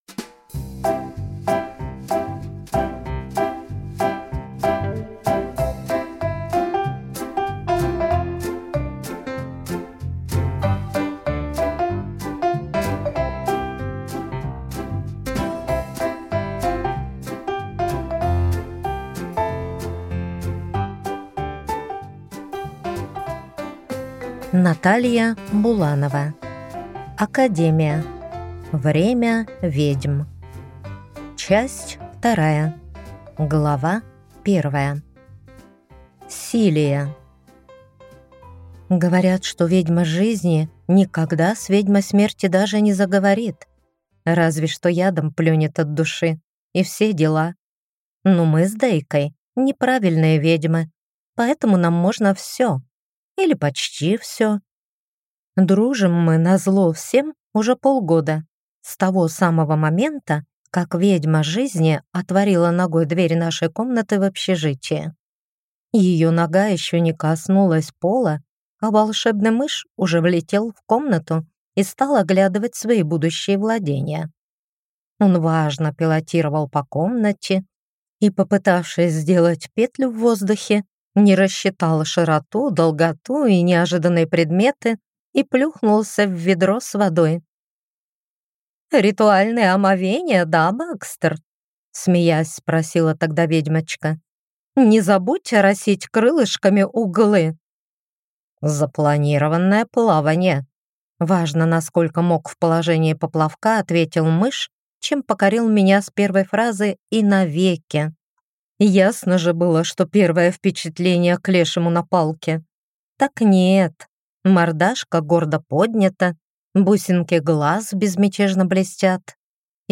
Аудиокнига Академия. Время ведьм | Библиотека аудиокниг
Прослушать и бесплатно скачать фрагмент аудиокниги